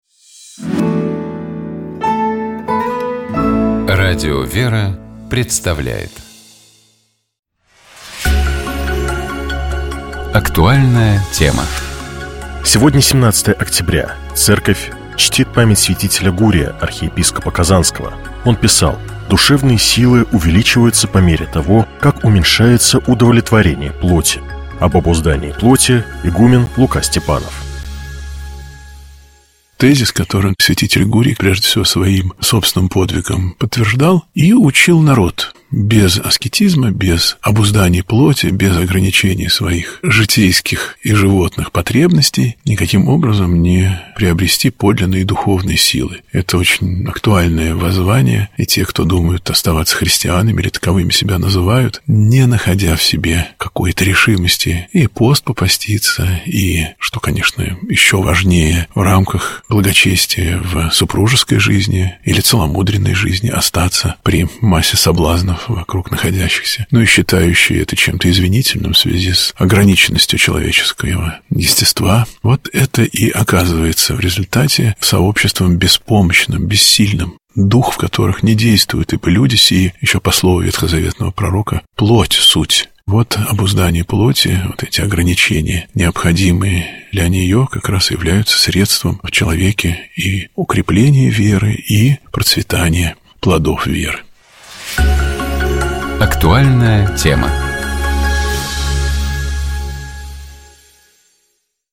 «Вечер воскресенья» - это разговор с людьми об их встрече с Богом и приходе к вере. Это разговор о том, как христианин существует в современном мире и обществе, как профессиональная деятельность может гармонично сочетаться с верой.